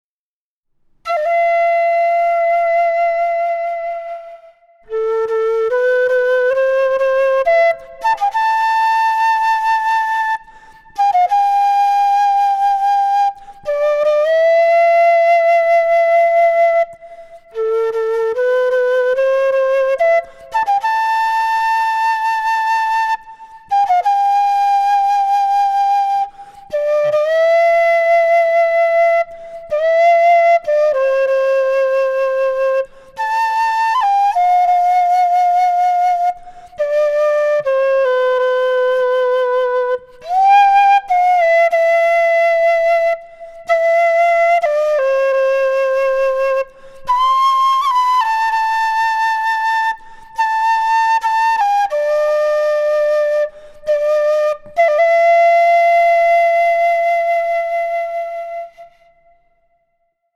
Кена (Quena, Jacaranda, Ramos, G) Перу
Материал: жакаранда.